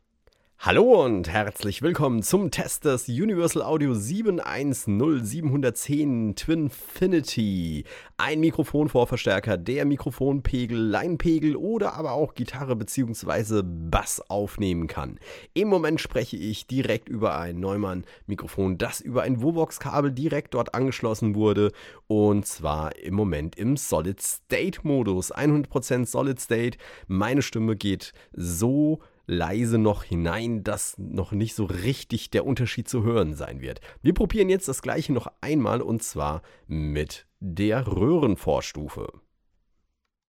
Im Bereich des Solid State klingt es sehr präzise, die Aufnahmen können durch Transparenz bestechen und sind weitestgehend linear.
In den Klangbeispielen findest Du zahlreiche Aufnahmen mit unterschiedlichen Einstellungen für Gain und Mischungsverhältnis der beiden Schaltkreise.